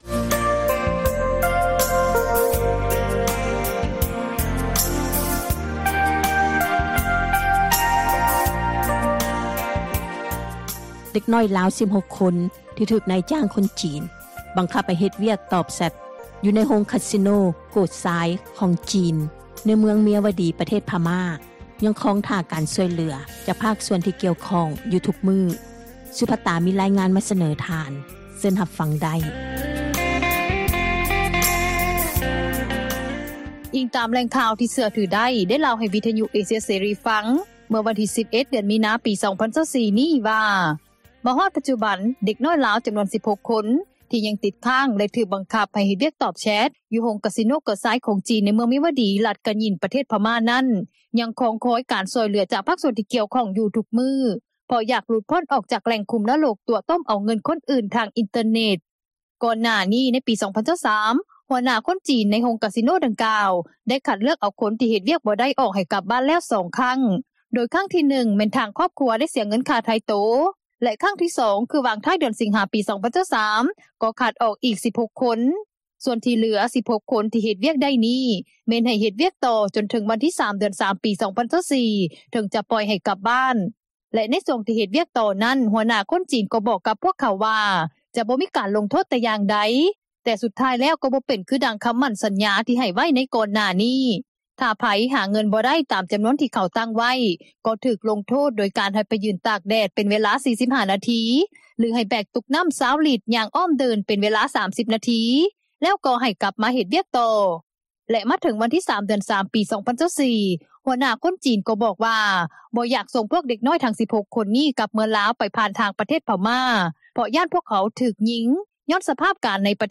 ທາງດ້ານຜູ້ປົກຄອງ ໃນແຂວງຫຼວງນໍ້າທາ ທີ່ລູກເຕົ້າ ຍັງຕິດຄ້າງ ຢູ່ໂຮງກາສິໂນ ໂກຊາຍ ໃນເມືອງເມັຍວະດີ ປະເທດພະມ້ານັ້ນ ກໍໄດ້ເວົ້າ ກ່ຽວກັບເຣື່ອງການ ຮ້ອງຮຽນຂໍການຊ່ອຍເຫຼືອພວກລູກເຕົ້າ ນໍາທາງການລາວ ແລະພາກສ່ວນທີ່ກ່ຽວຂ້ອງມາດົນນານ ດ້ວຍຄວາມທໍ້ໃຈ ວ່າ: